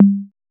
8000 CONGA.wav